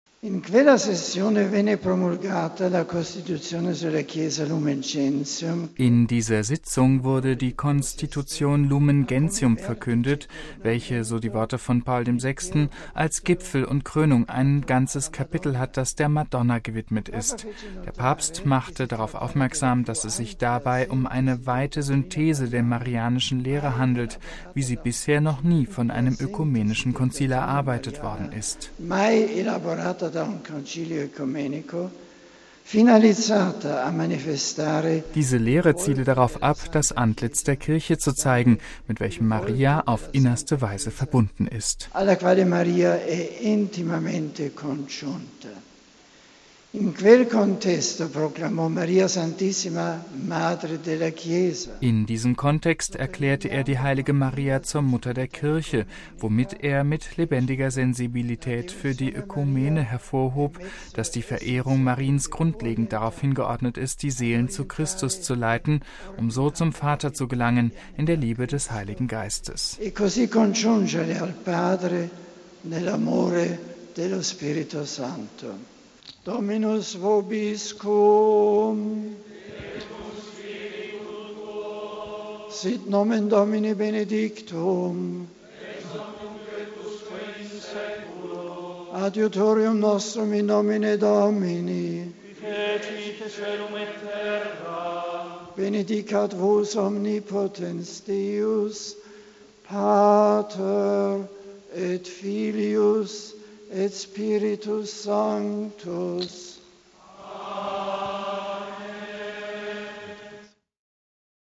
Darauf hat Benedikt beim Angelusgebet im Anschluss die Sonntagsmesse in Brescia hingewiesen.